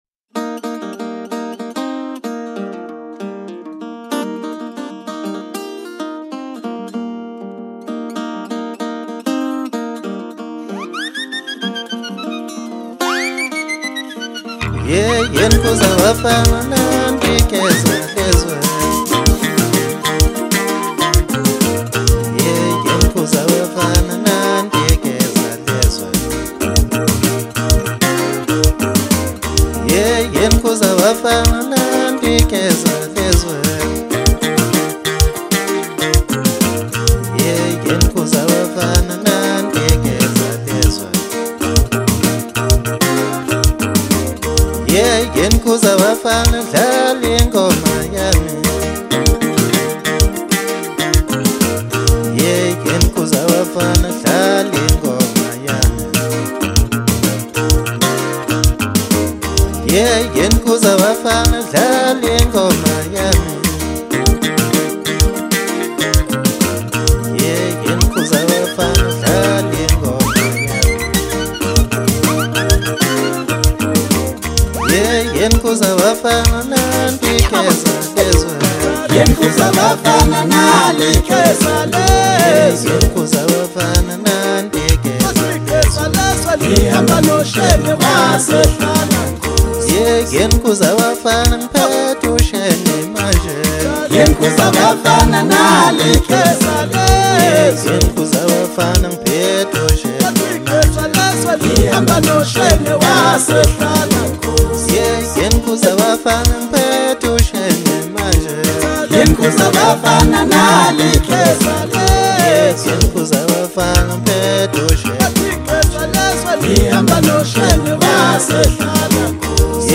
South African singer-songsmith